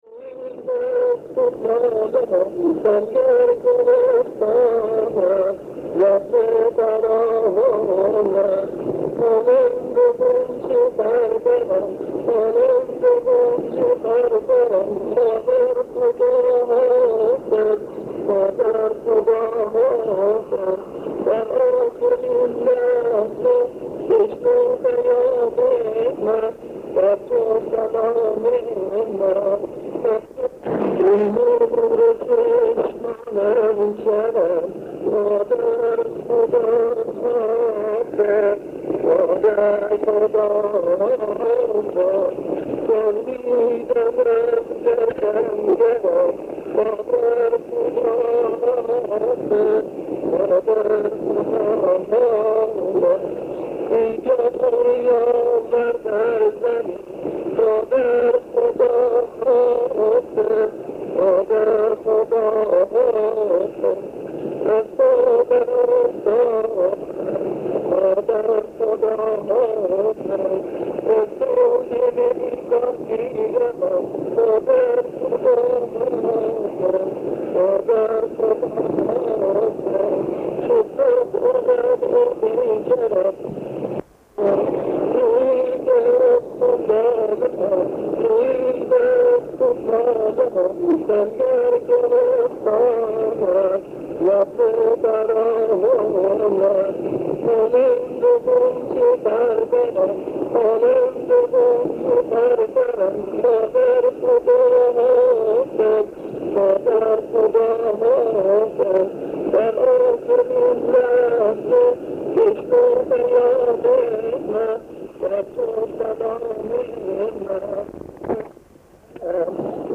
نوای مداحی